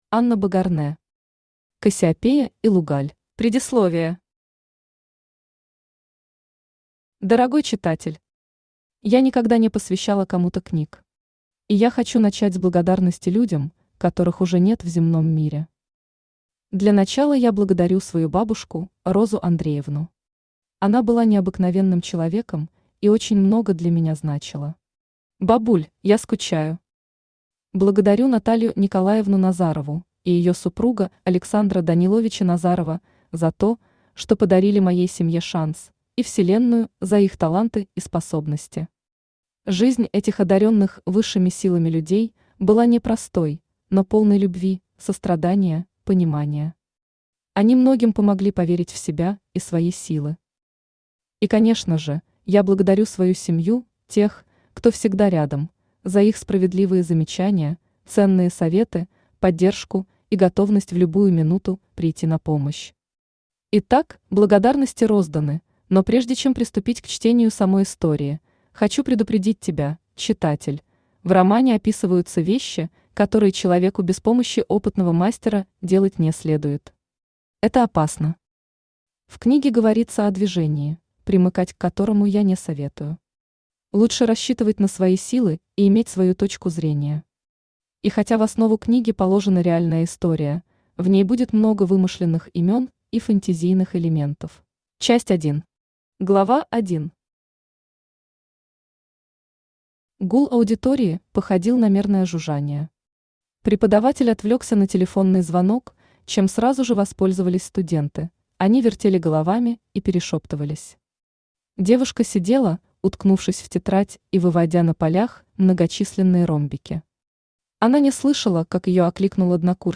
Аудиокнига Кассиопея и Лугаль | Библиотека аудиокниг
Aудиокнига Кассиопея и Лугаль Автор Анна Богарнэ Читает аудиокнигу Авточтец ЛитРес.